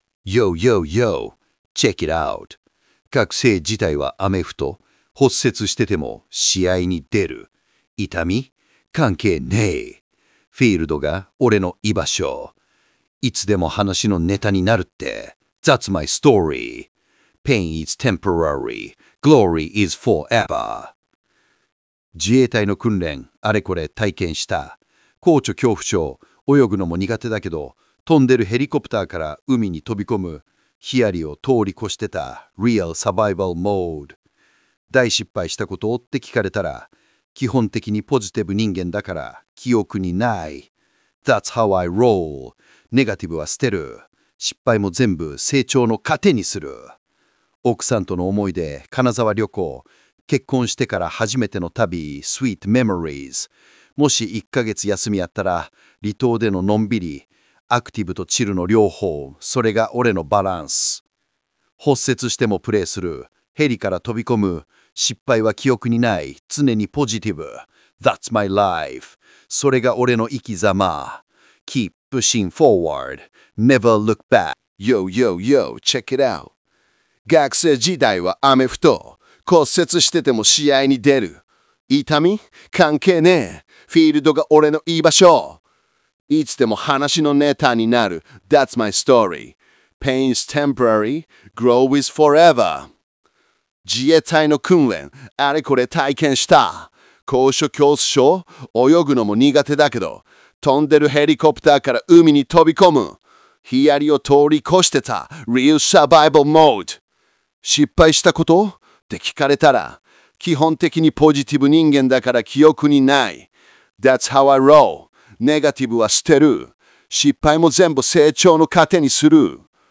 ▲ラッパー風のAI音声とともに出題。
最後にそのクイズ文章を、Google AI Studioの「Text to speech with Gemini」に読み込ませて音声を作成し、ランチ交流会当日は「音声付きクイズ」として出題しました！